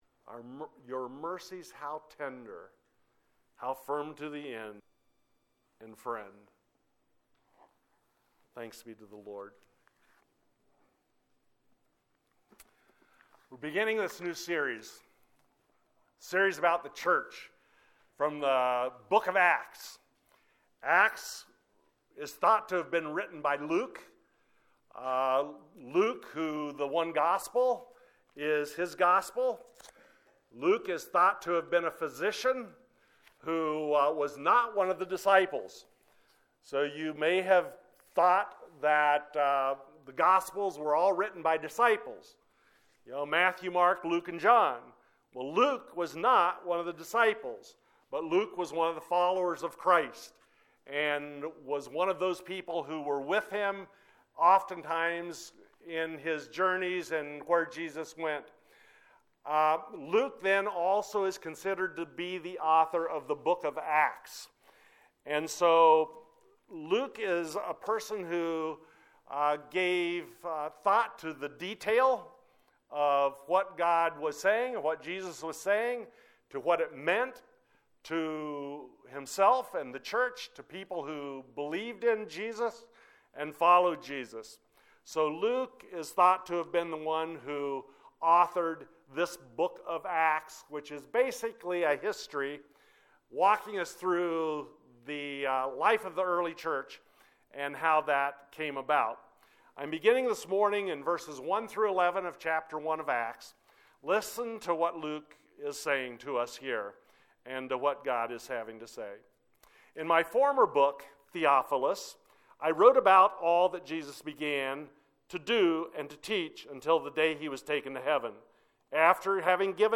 wp-content/uploads/2021/07/Our-Mission.mp3 A sermon from Acts 1:1-11 Upcoming Events view all upcoming events Abilene BIC Be our guest!